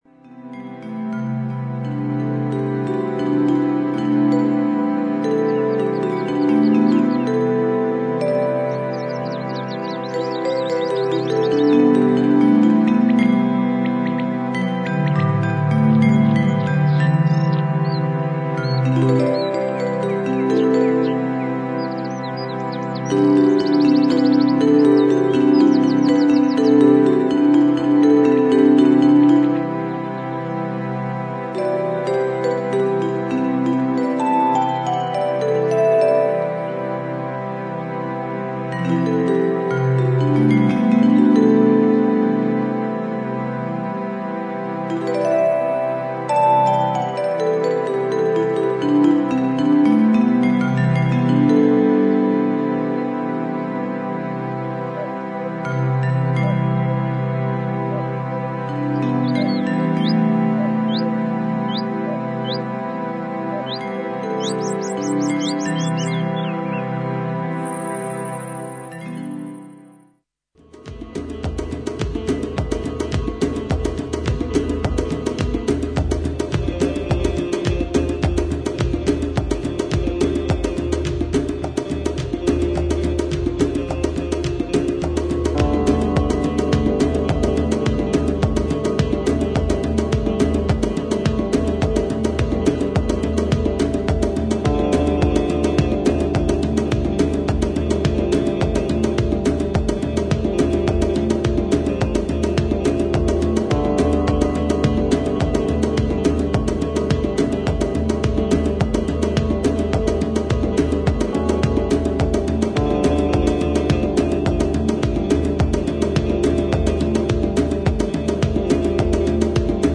プリミティブなパーカッションの上をミニマルな上音が浮遊する
リフレインするエレピとドラムが絡み合い展開する
オーガニックな質感のドラムとパーカッションに、どこかアンニュイなシンセのフレーズが加わる
程よくダビーに処理されたリズムに、オルガンやホーンのゆったりとしたフレーズが乗る